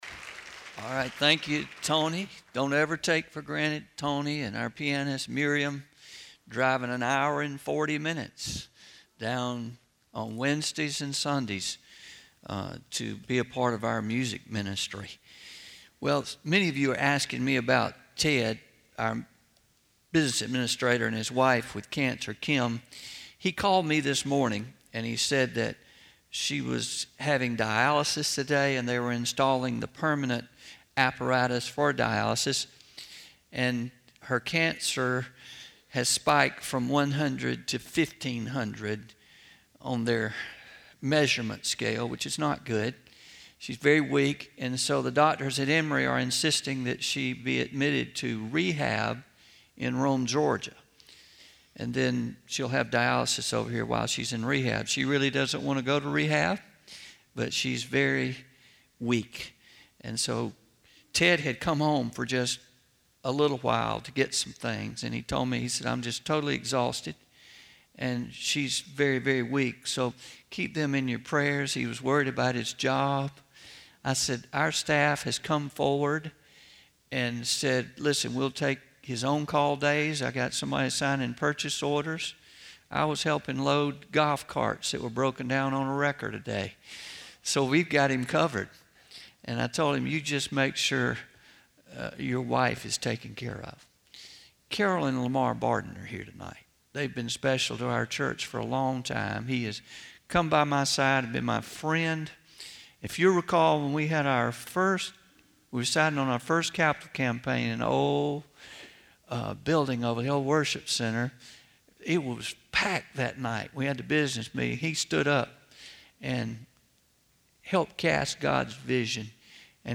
09-27-23pm Sermon – When Frustration Sets In